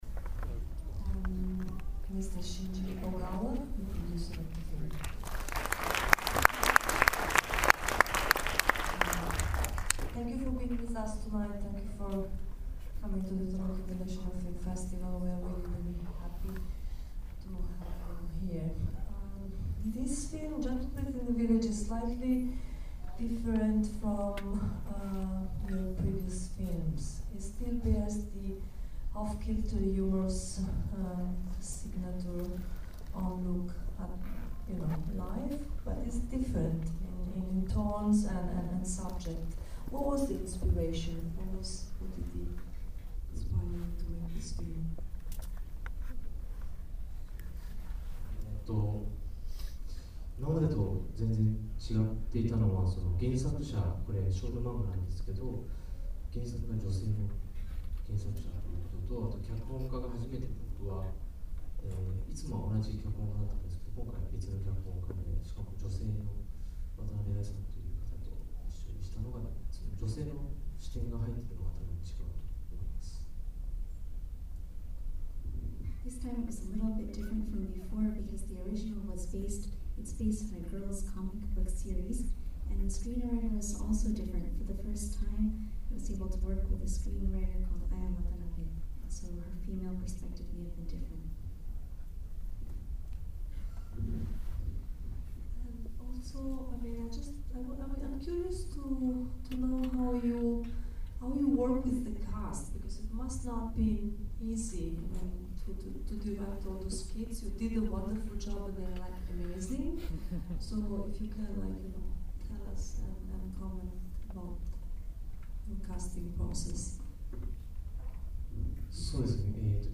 gentlebreeze_qa.mp3